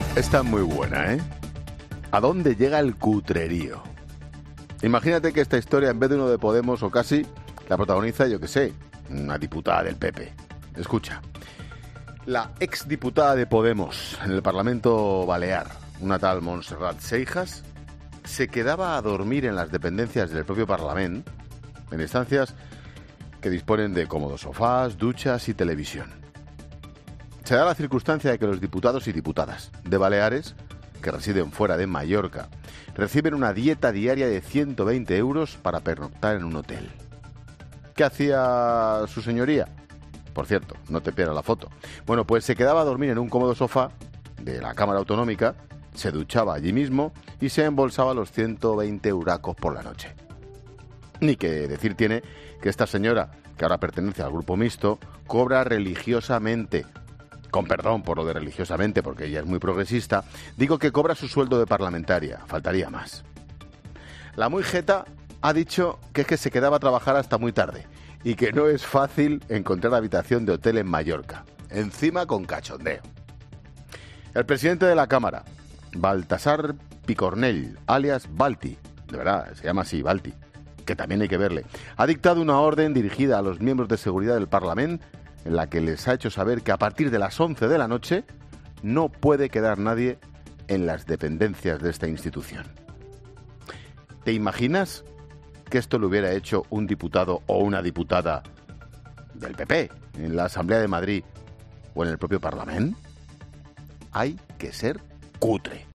Monólogo de Expósito
Monólogo de Ángel Expósito a las 17h. sobre Montserrat Seijas, ex diputada de Podemos en el parlamento balear, tras conocerse que durmió dos noches en sus dependencias.